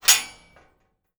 LOCK_Metal_Sliding_06_mono.wav